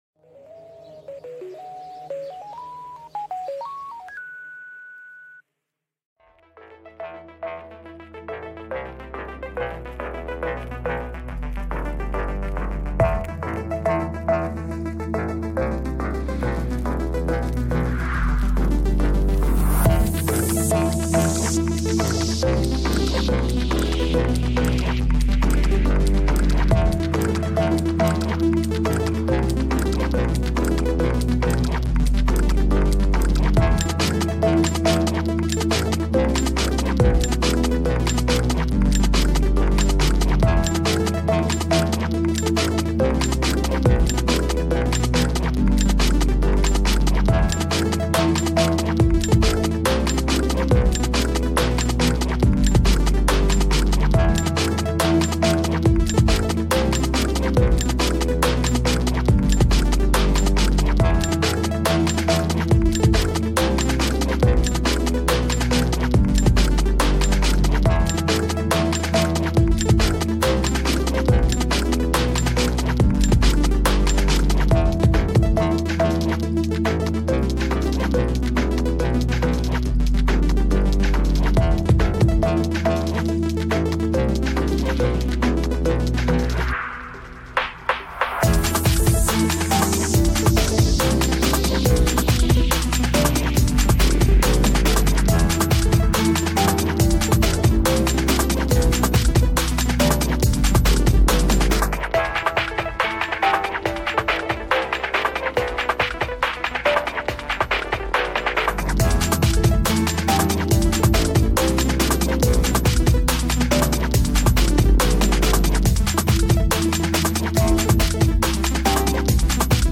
garage stuff